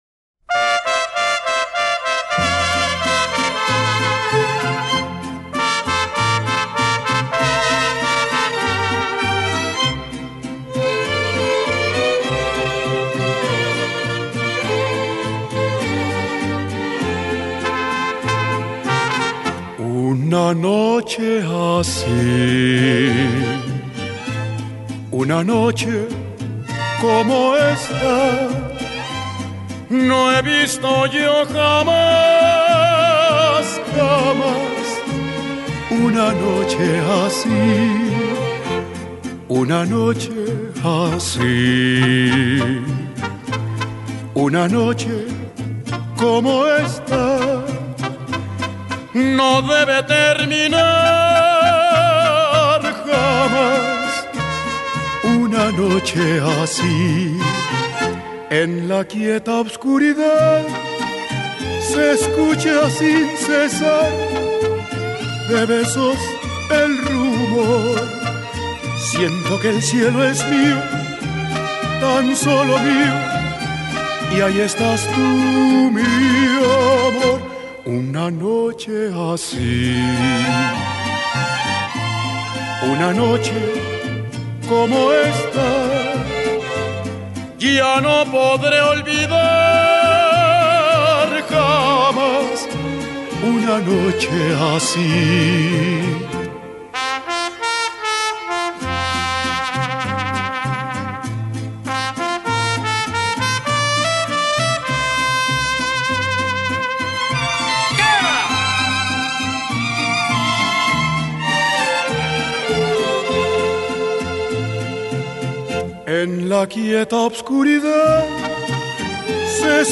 Las pausas y silabeo describen la particular noche.